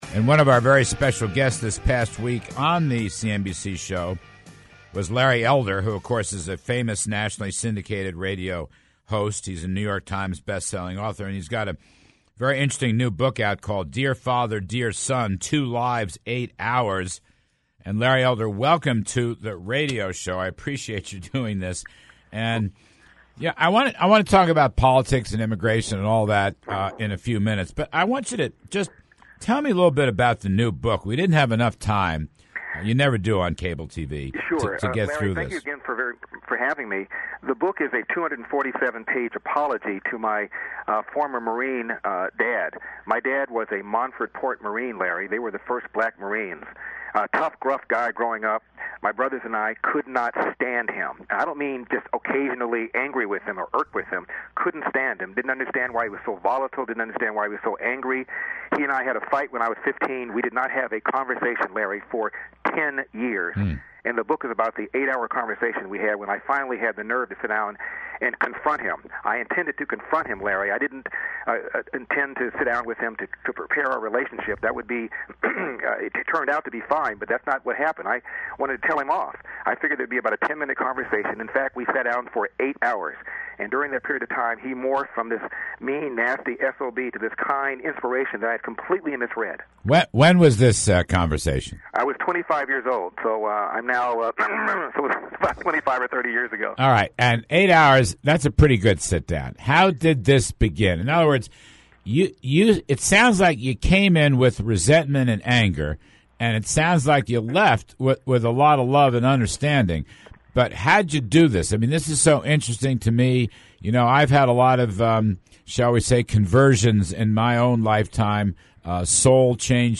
Radio talkshow host/bestselling author LARRY ELDER discusses his new book, "Dear Father, Dear Son" (WND Books) with Larry Kudlow on his weekly radio program as well as issues effecting Americans this week.